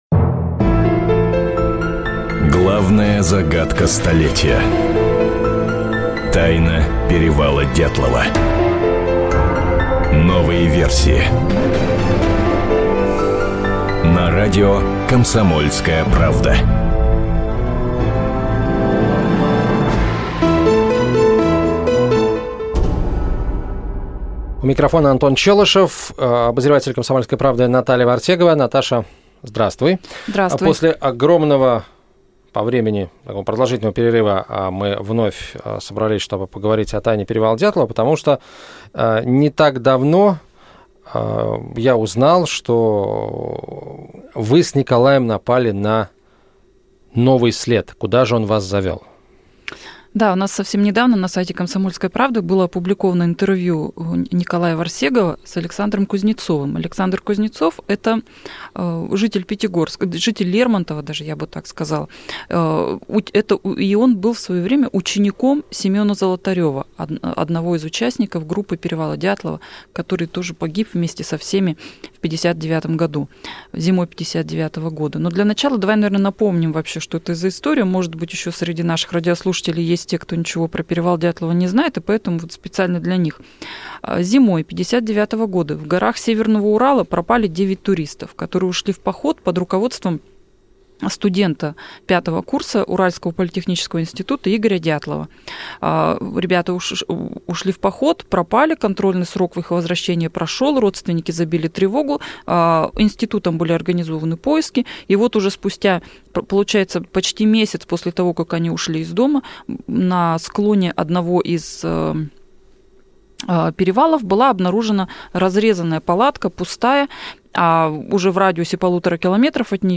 Аудиокнига Тайна перевала Дятлова. Новый след | Библиотека аудиокниг